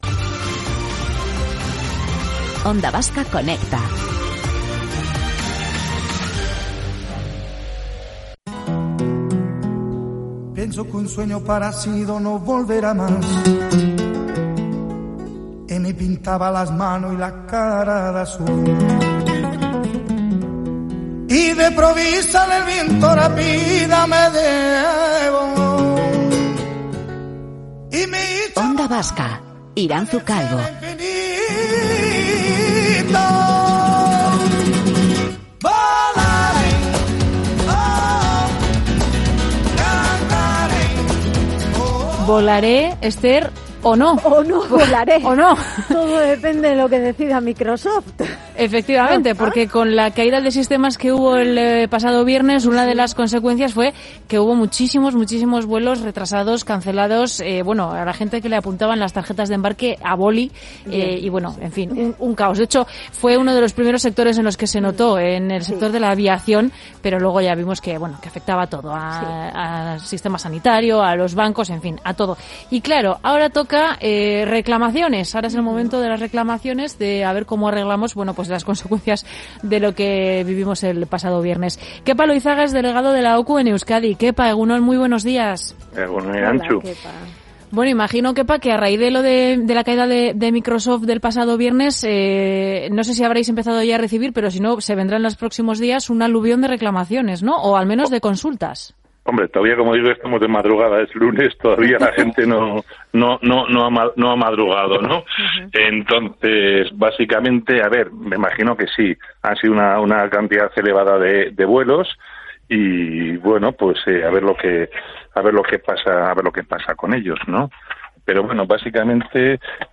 Morning show conectado a la calle y omnipresente en la red.